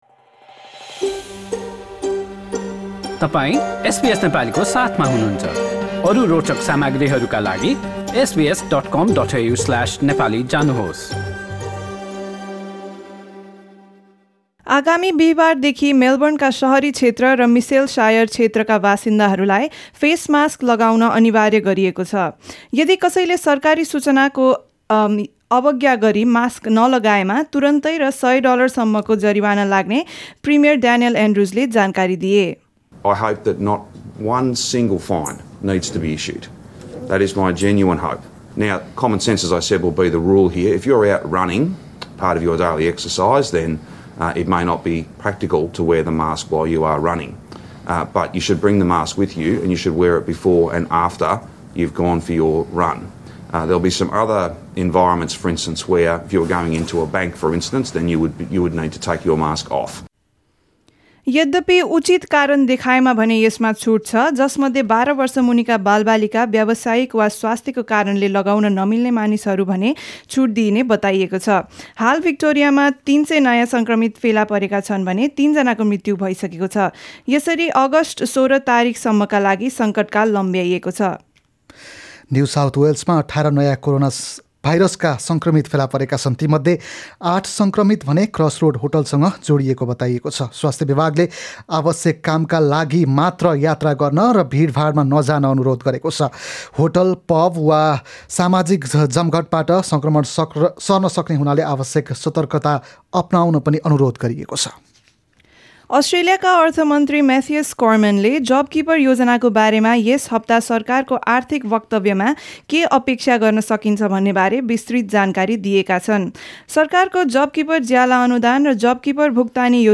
एसबीएस नेपाली अस्ट्रेलिया समाचार: आइतवार १९ जुलाई २०२०